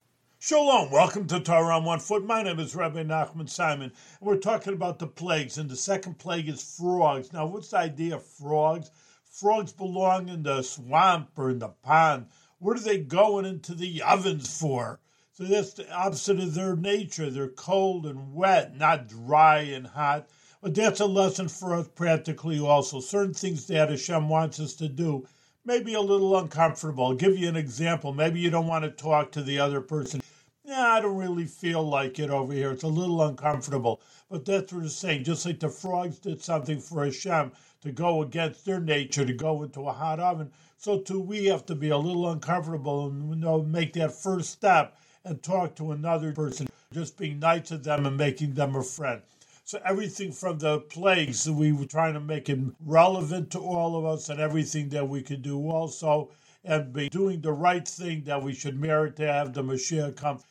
One-minute audio lessons on special points from weekly Torah readings in the Book of Exodus.